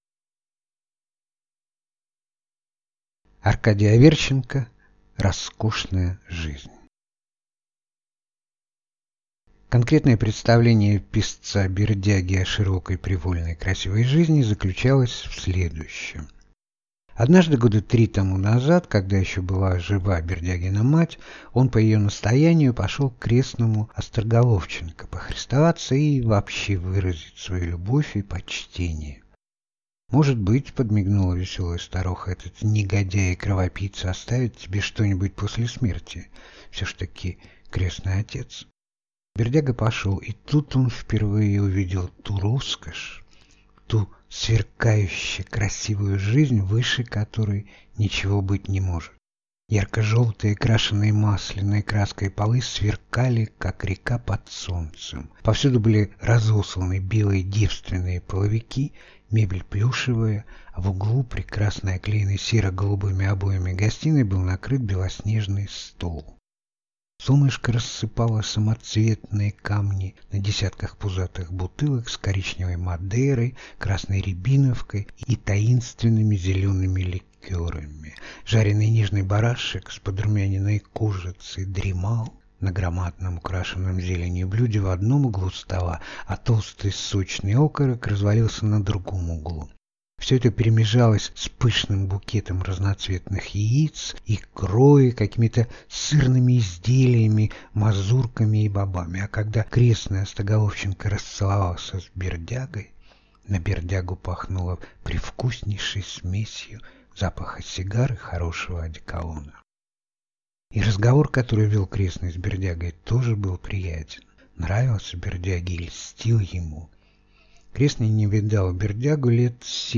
Аудиокнига Роскошная жизнь | Библиотека аудиокниг